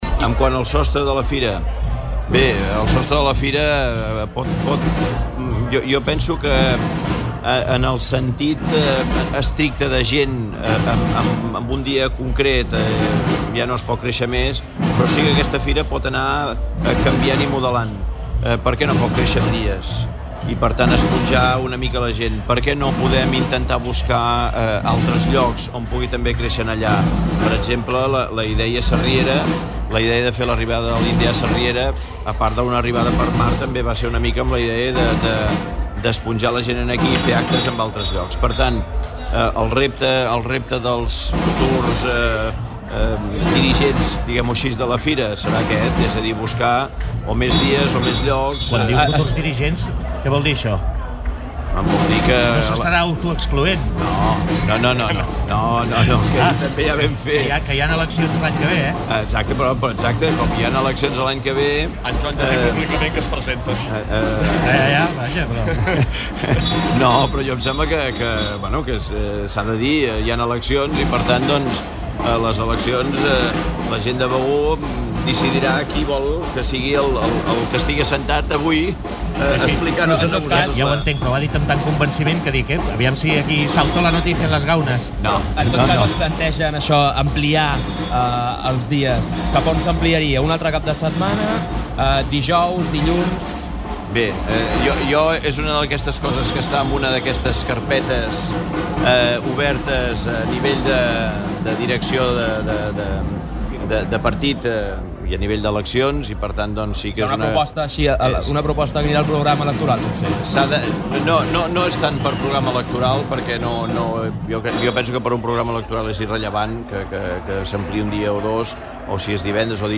L’alcalde de Begur Joan Loureiro ha assegurat en una entrevista en directe des de la Fira d’Indians que l’esdeveniment té el repte d'”esponjar-se” i créixer en dies i en ubicacions, encara que afirma que aquestes qüestions seran “carpetes obertes” del futur govern que surti de les eleccions de l’any que ve.